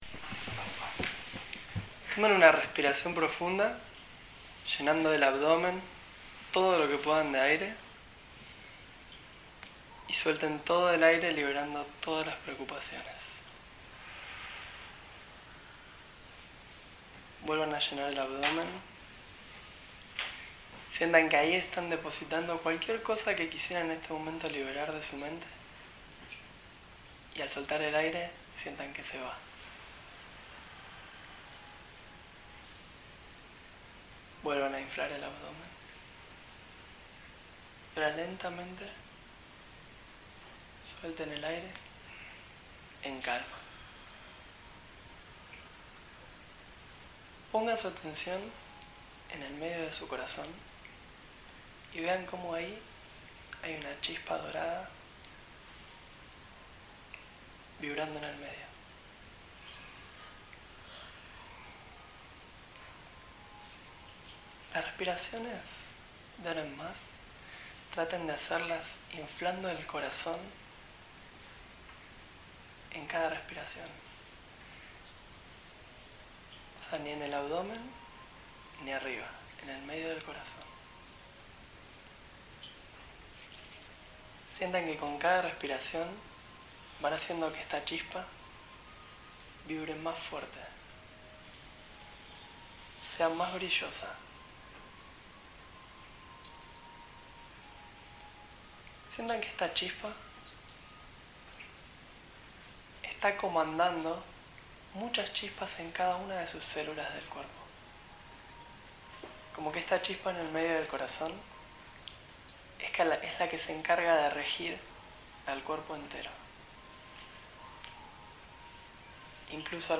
Meditación gratuita en Buenos Aires – Tema: Conexión con la Red de Amor Universal
En Capital Federal, Argentina.